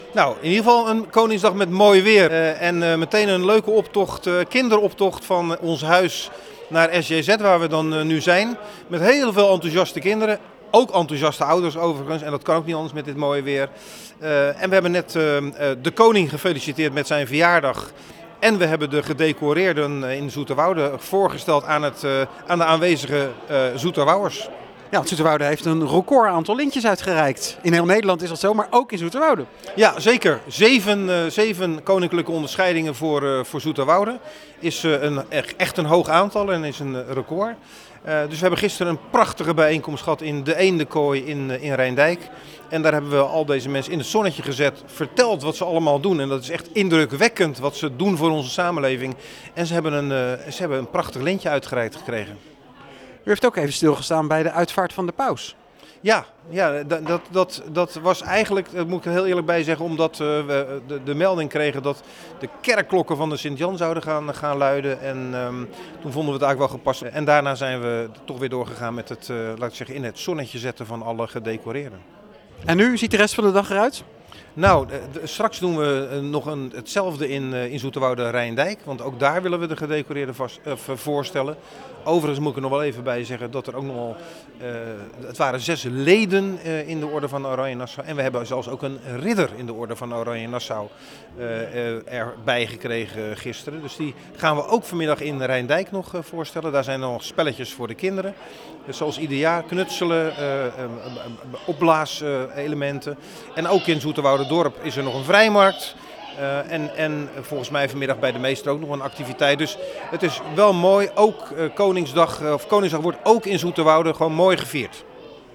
in gesprek met burgemeester Fred van Trigt over Koningsdag in Zoeterwoude.